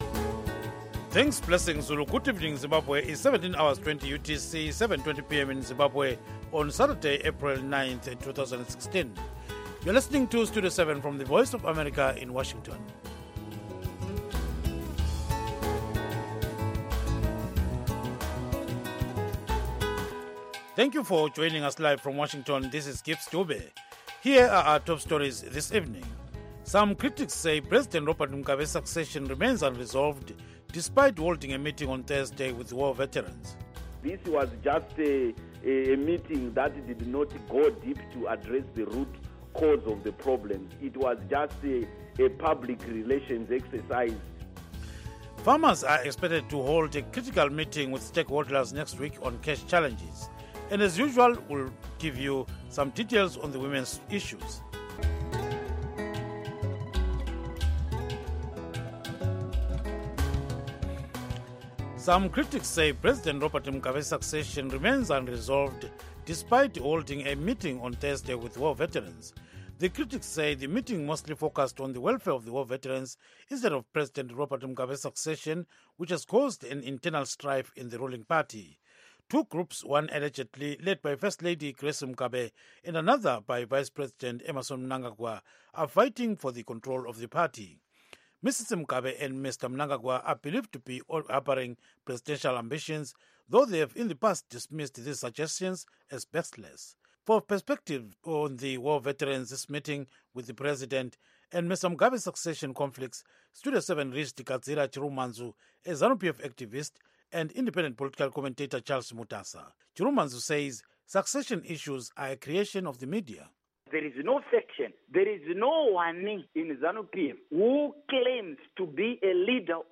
News in English